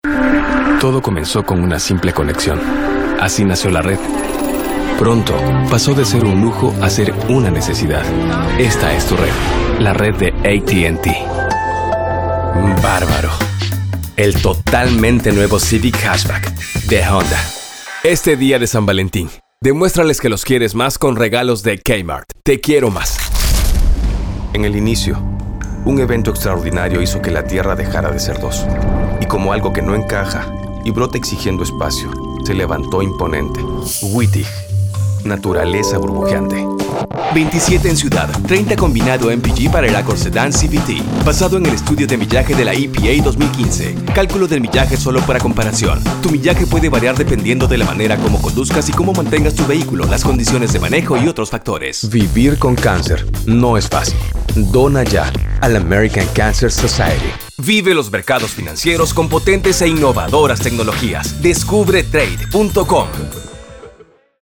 Languages: Spanish (Latin American) Accent: Mexican, South American (Argentinian), South American (Peruvian) Ages: Middle Aged, Senior, Teen, Young Adult Special Skills: Animation, Audiobooks, Business, Documentaries, Educational, Internet Video, Movie Trailers, Podcasting, Radio, Telephone, Television, Videogames
Sprechprobe: eLearning (Muttersprache):
NATURAL JOVEN MADURO 3.mp3